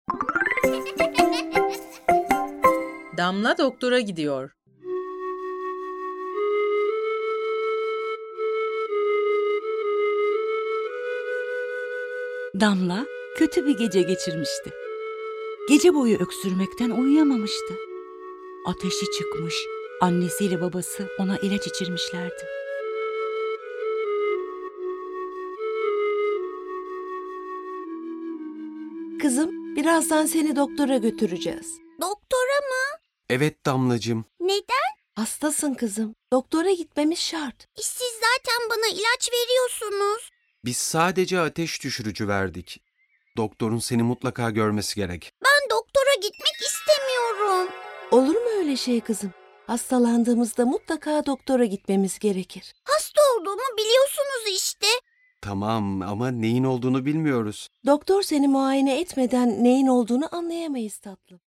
Damla Doktora Gidiyor sesli tiyatrosu ile eğlenceli ve eğitici bir maceraya çıkın.